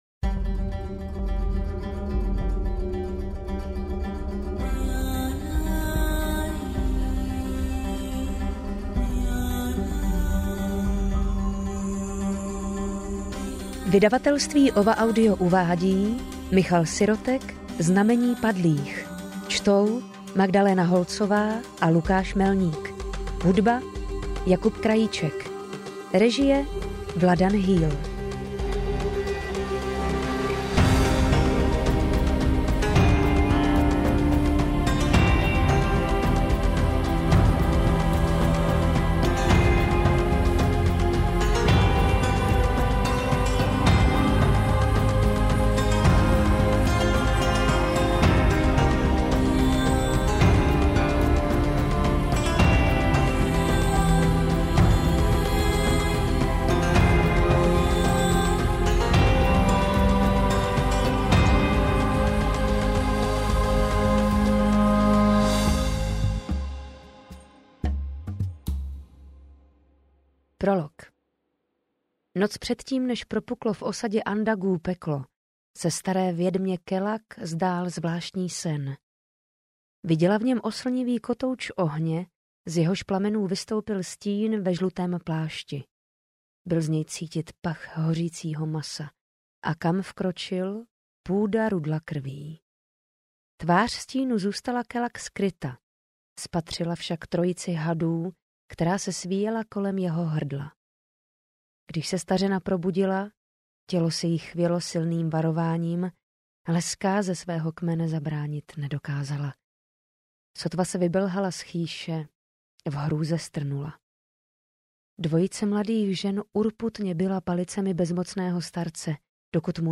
Znamení padlých audiokniha
Ukázka z knihy